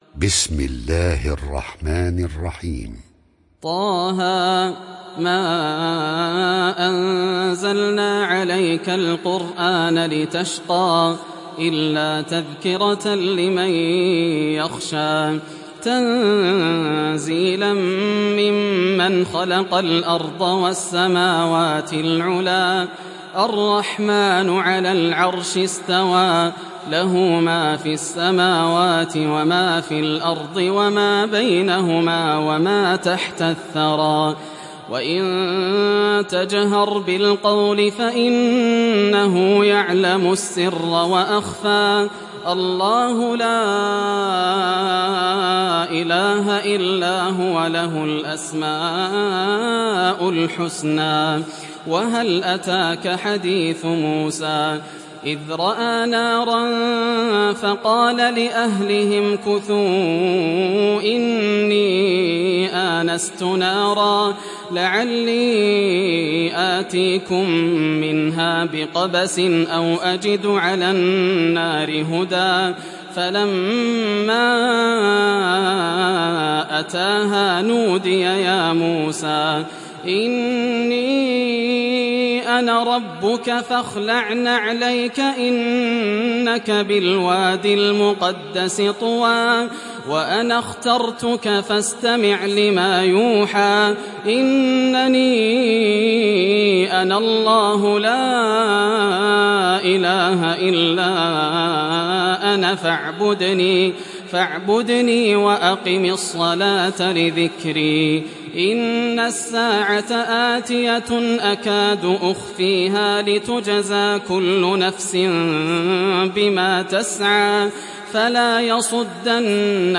Taha Suresi İndir mp3 Yasser Al Dosari Riwayat Hafs an Asim, Kurani indirin ve mp3 tam doğrudan bağlantılar dinle